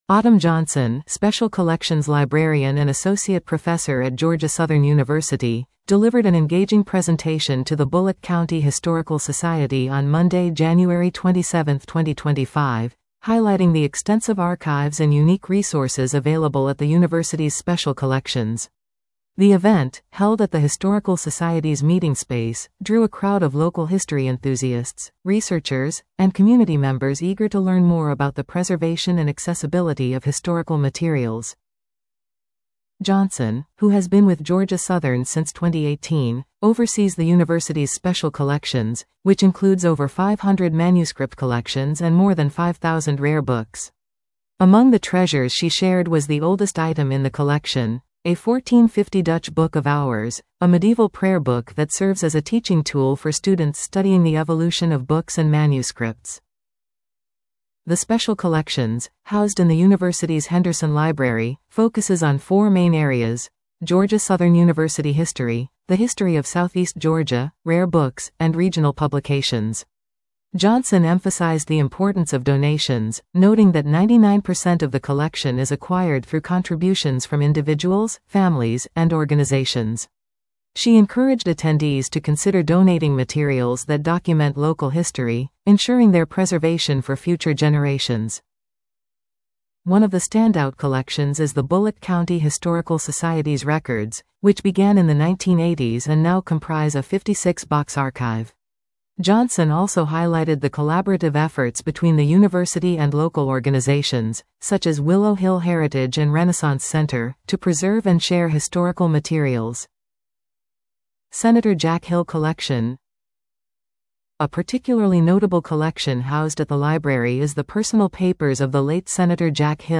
The event, held at the Historical Society’s meeting space, drew a crowd of local history enthusiasts, researchers, and community members eager to learn more about the preservation and accessibility of historical materials.